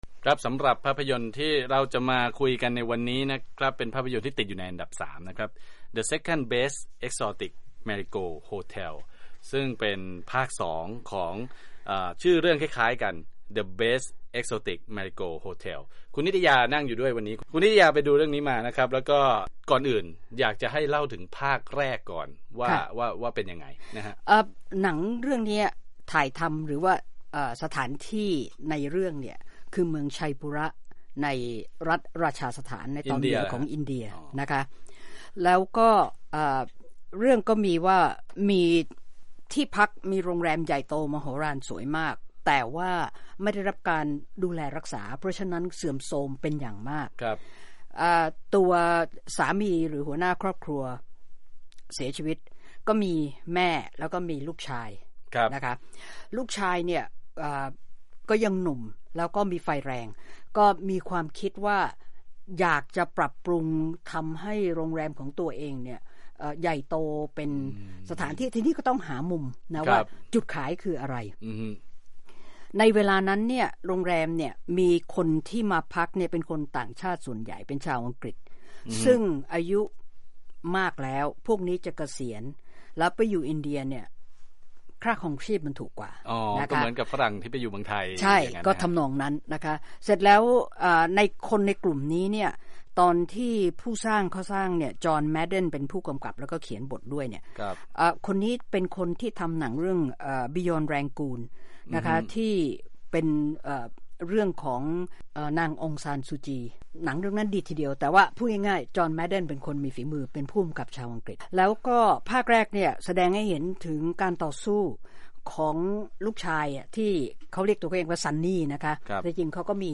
movie-review-second-best-exotic-marigold-hotel